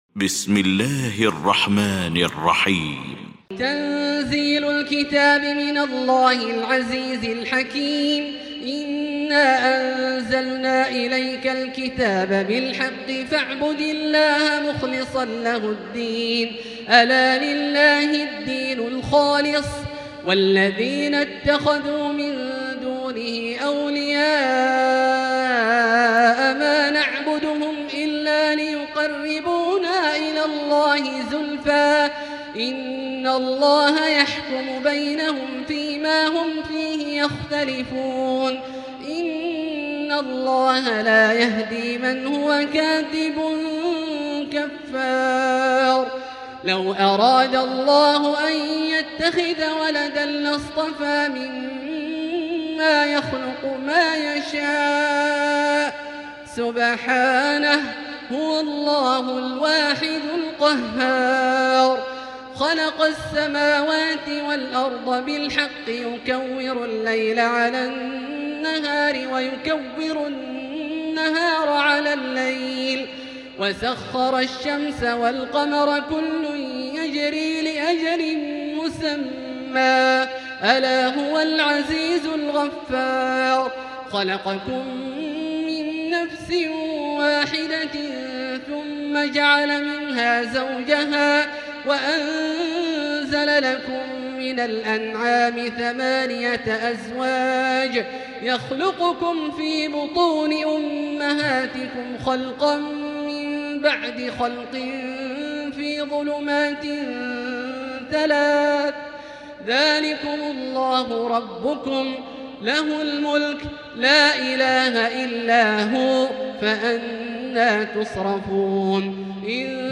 المكان: المسجد الحرام الشيخ: سعود الشريم سعود الشريم معالي الشيخ أ.د. عبدالرحمن بن عبدالعزيز السديس فضيلة الشيخ عبدالله الجهني الزمر